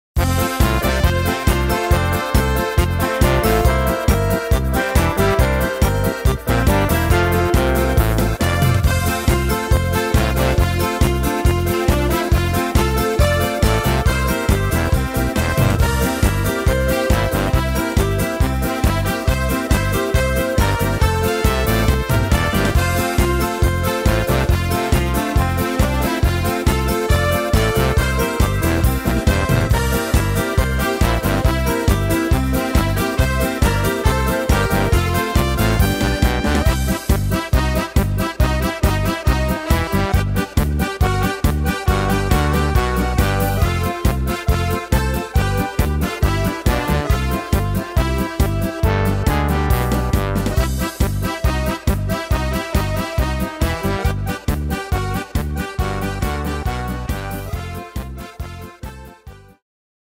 Tempo: 138 / Tonart: F-Dur